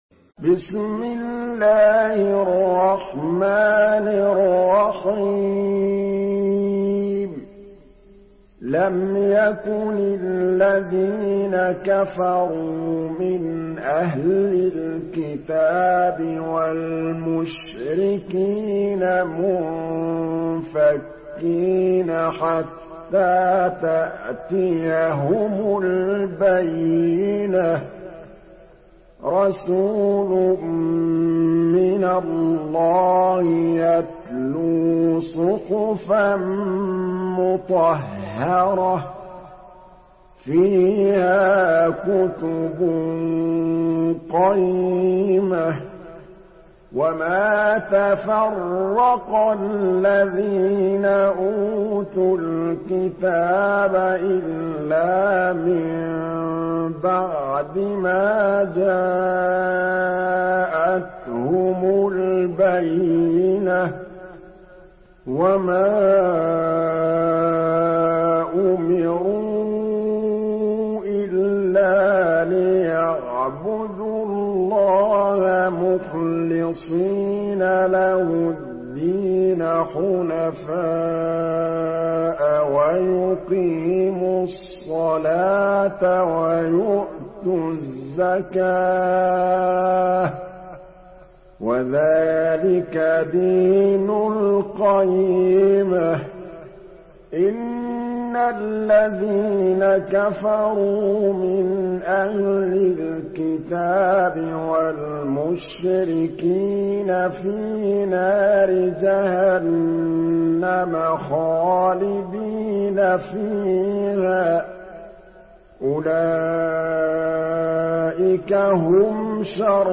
Surah আল-বায়্যিনাহ্ MP3 by Muhammad Mahmood Al Tablawi in Hafs An Asim narration.
Murattal Hafs An Asim